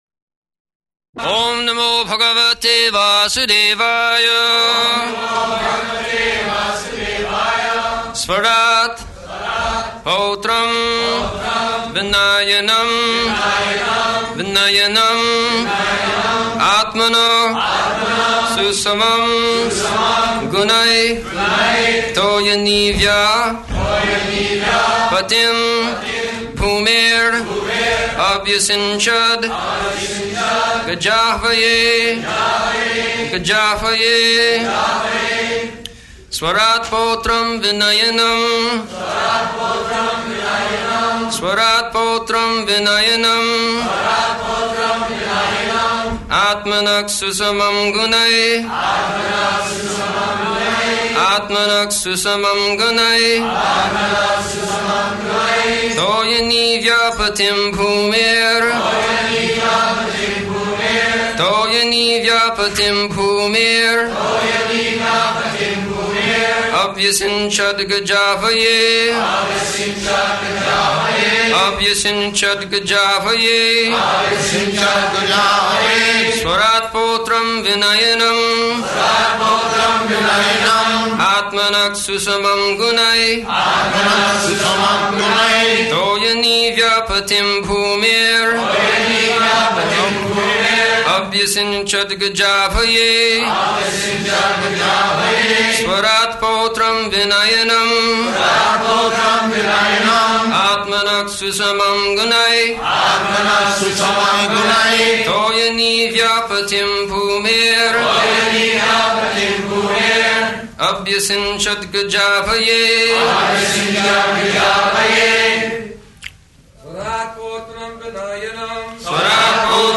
December 16th 1973 Location: Los Angeles Audio file
[leads chanting of verse] [Prabhupāda and devotees repeat]
[laughter] It was known.